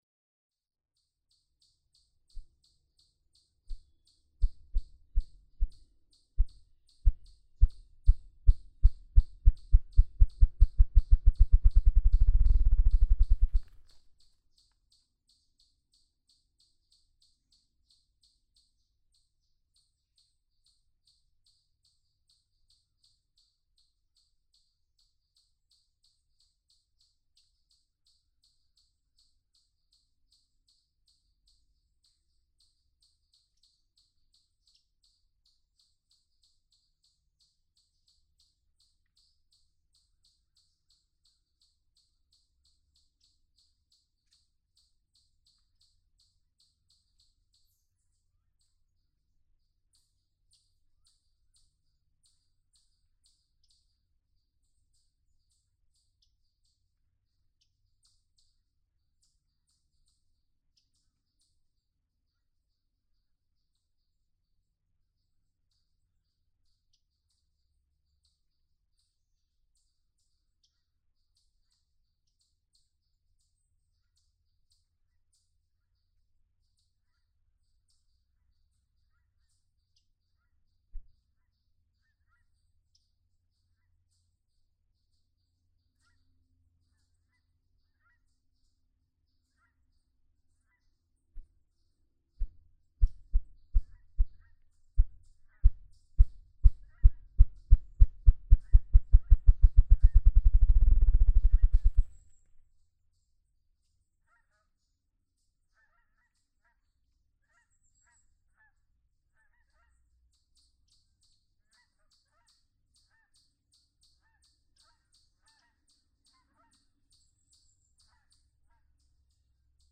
Ruffed grouse
A typical drum that can be heard across North America, example 1. Ten drums, delivered at a faster than average grouse speed, about one every two minutes.
Quabbin Park, Ware, Massachusetts.
573_Ruffed_Grouse.mp3